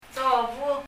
« seed 種 telephone 電話 » gutter / ditch 溝・どぶ dobu [dɔbu] 「どぶ」って、もう日本では死語なんでは？